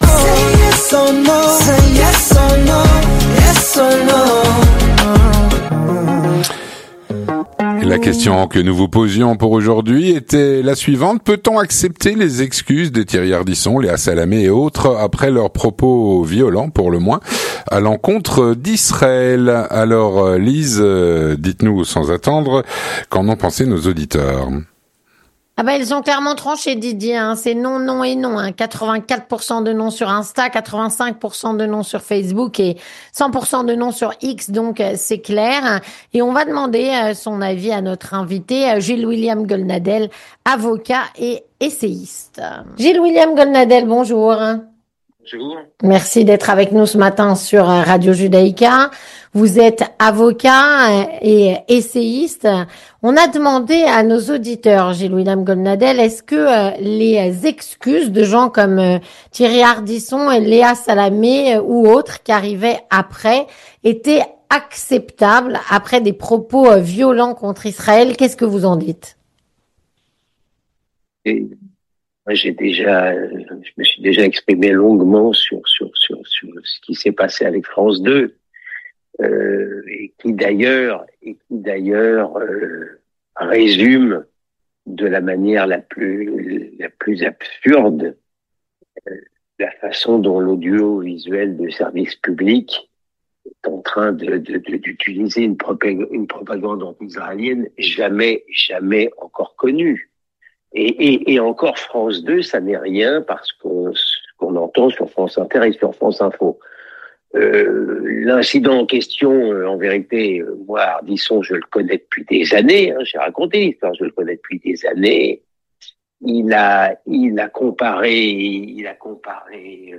Gilles-William Goldnadel , Avocat et essayiste, auteur de "Journal d'un prisonnier" (Fayard), répond à "La Question Du Jour".